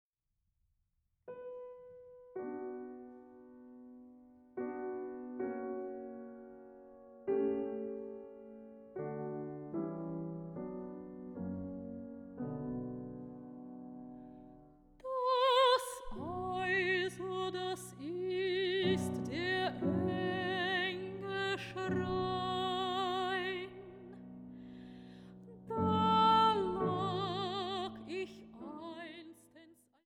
Sopranistin
Pianistin